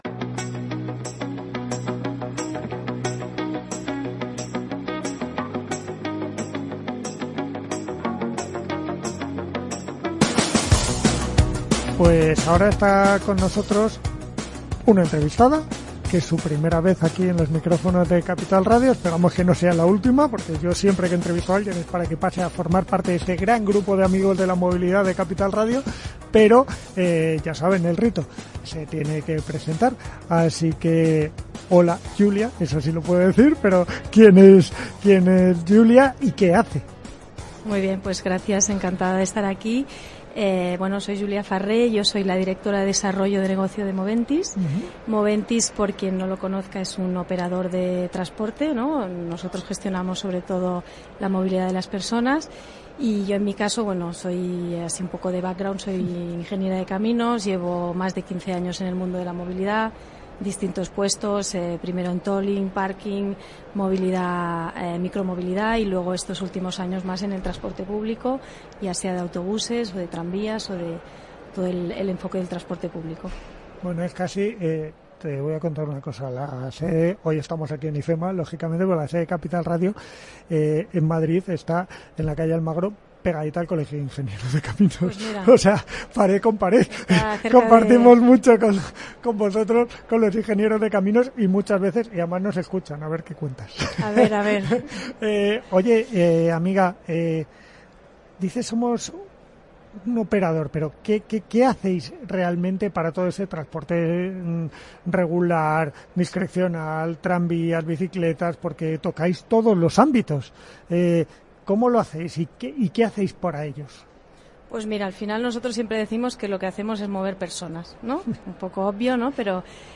Entrevista en el marc de la UITP